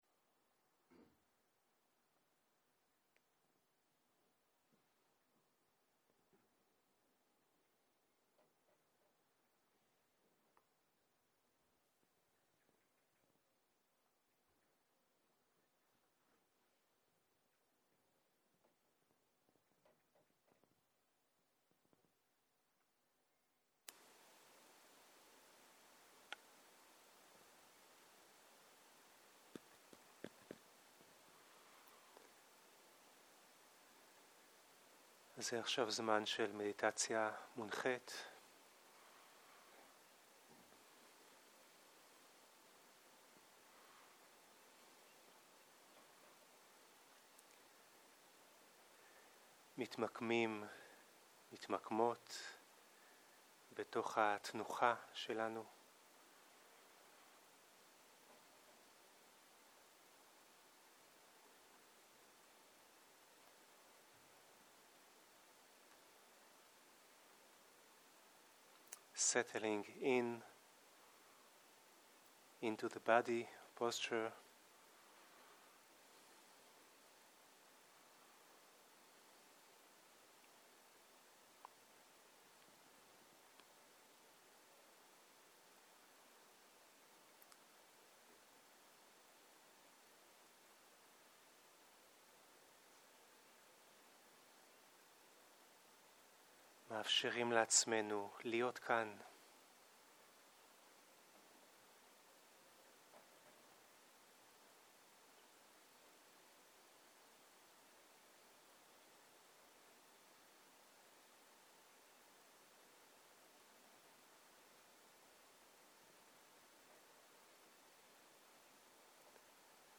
צהריים - מדיטציה מונחית